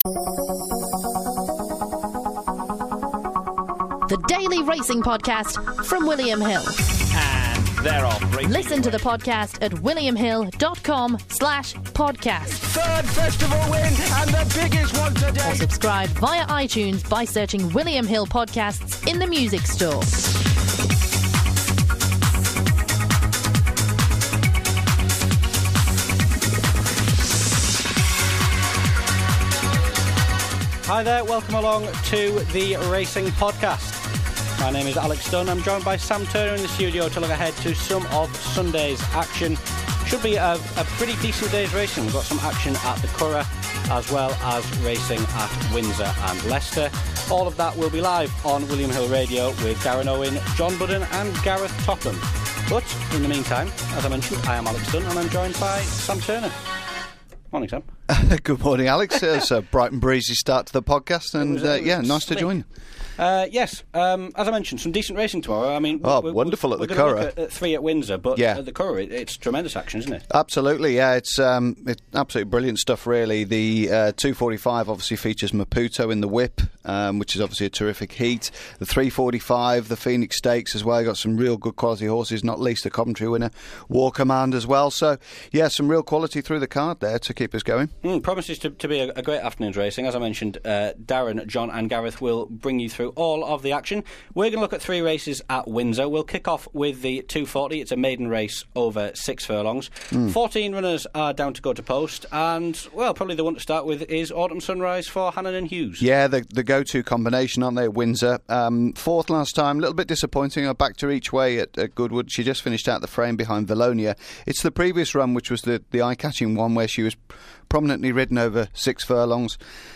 in the studio to look ahead to three races at Windsor